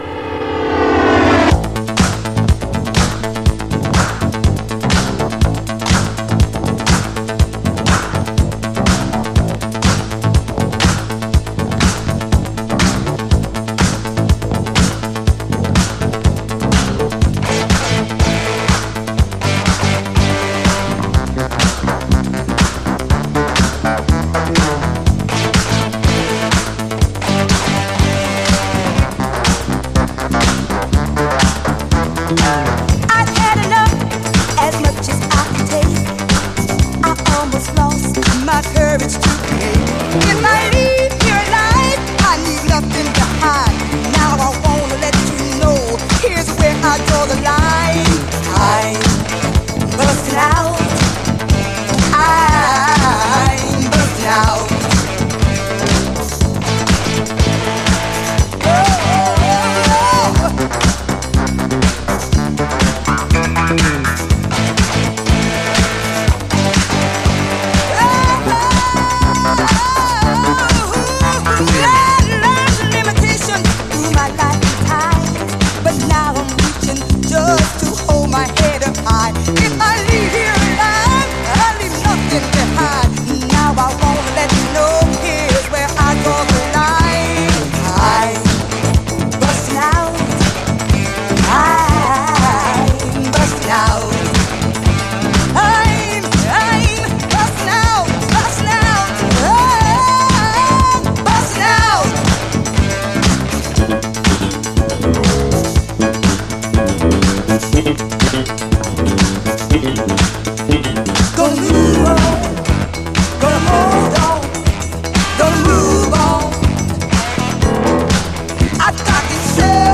絶妙にライトなニューウェーヴ感と、UKラヴァーズ的な甘酸っぱさ。
ダビーなディレイ処理とピアノの配し方がオシャレです。